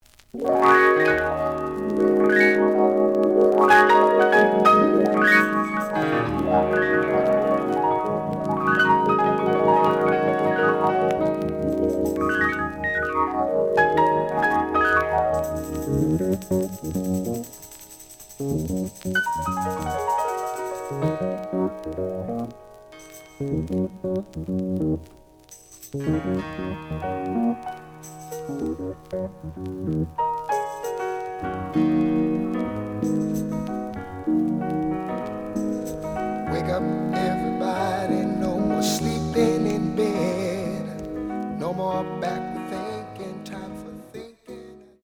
The audio sample is recorded from the actual item.
●Genre: Soul, 70's Soul
Slight noise on beginning of A side, but almost good.)